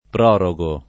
prorogo [ pr 0 ro g o ], ‑ghi